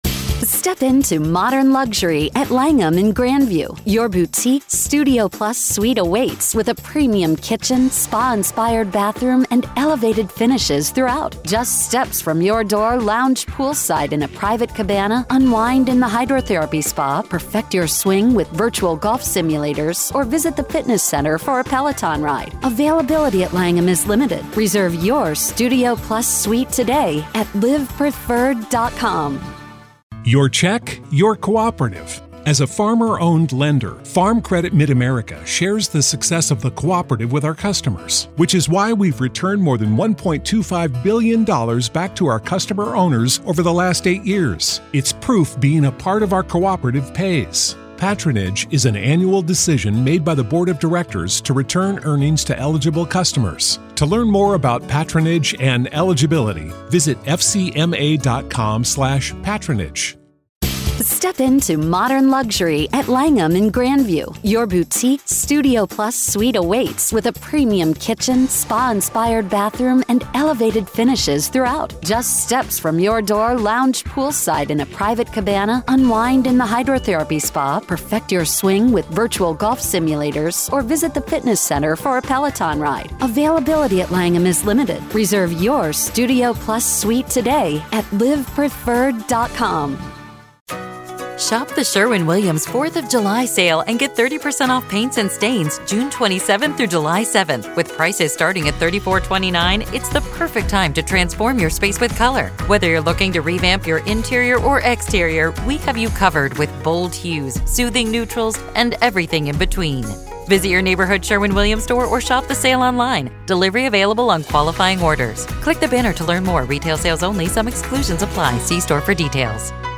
If you think you’ve seen defense tactics go low, this conversation asks the question: how far is too far in the pursuit of reasonable doubt?